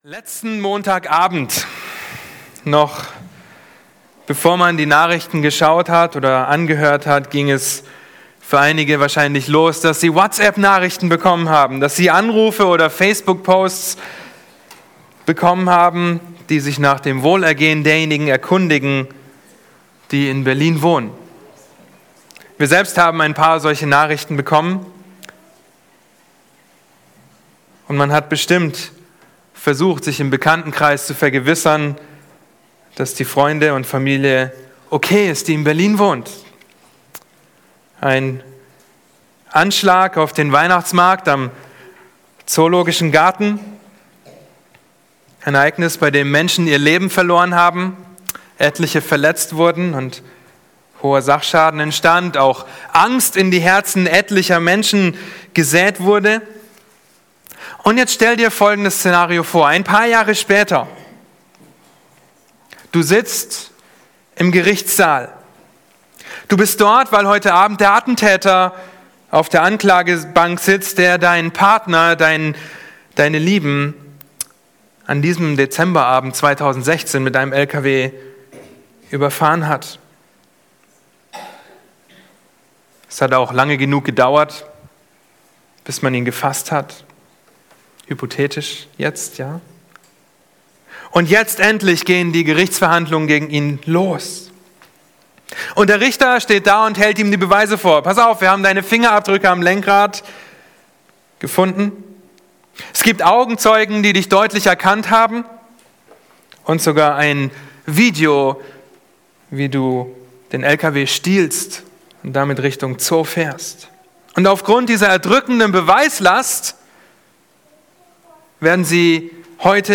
Predigt zu Weihnachten 2016